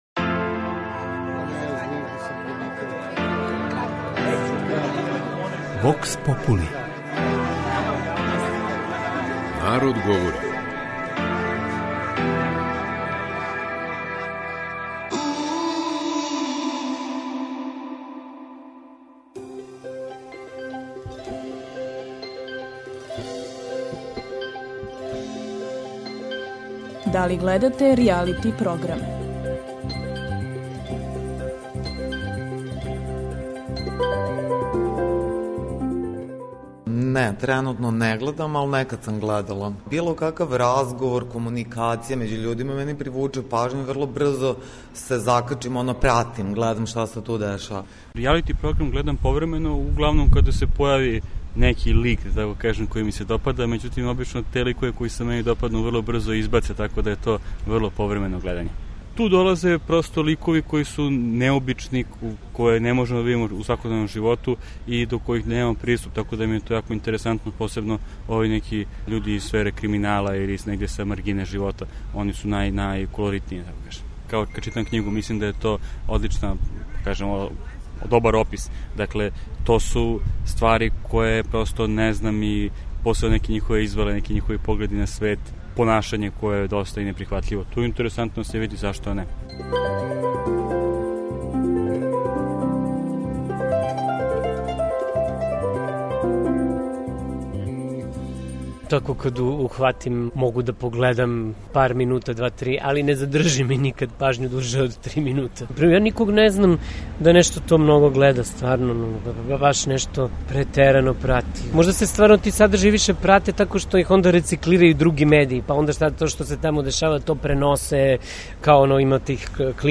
кроз кратке монологе, анкете и говорне сегменте у којима ће случајно одабрани, занимљиви саговорници одговарати